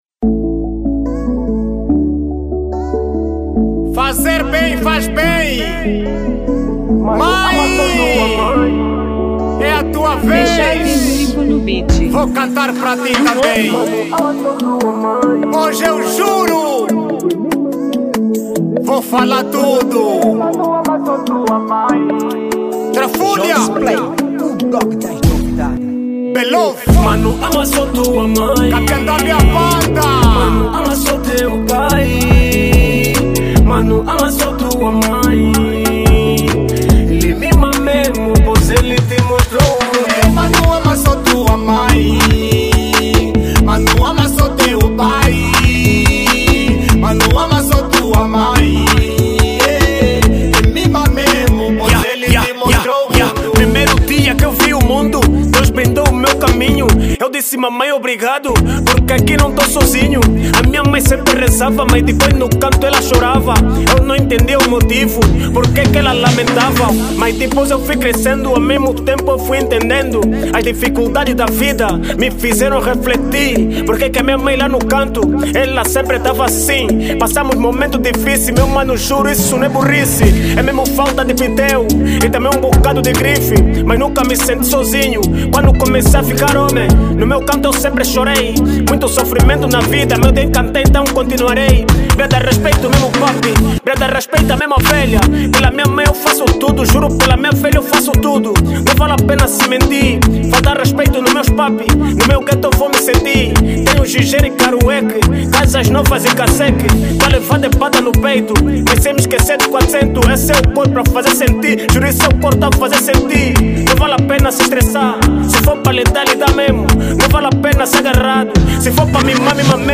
| Kuduro